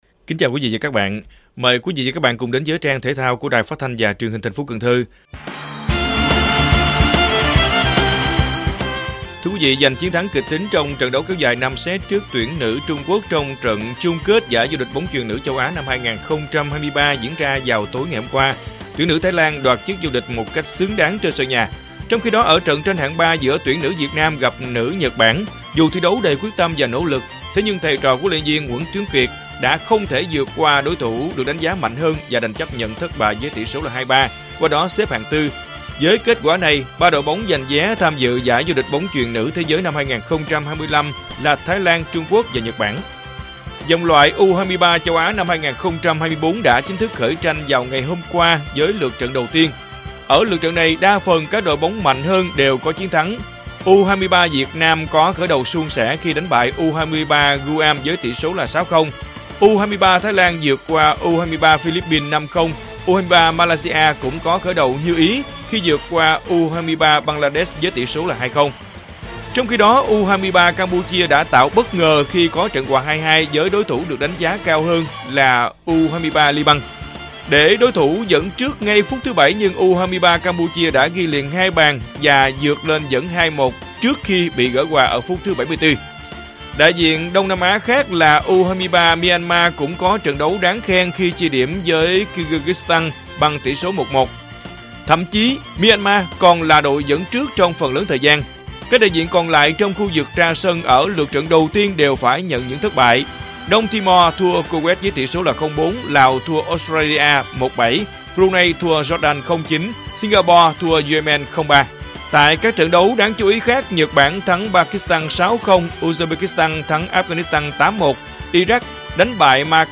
Bản tin thể thao 7/9/2023